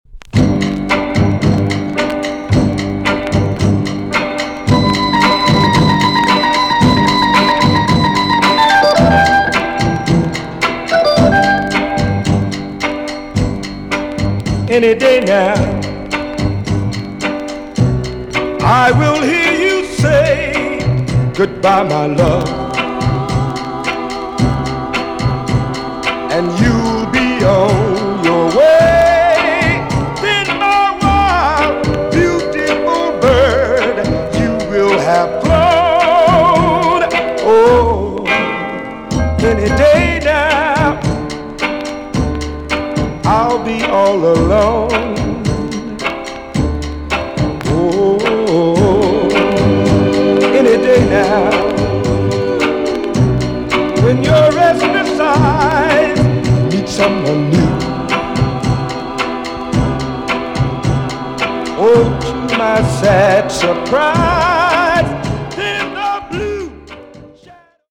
TOP >JAMAICAN SOUL & etc
EX-~VG+ 少し軽いチリノイズが入りますが良好です。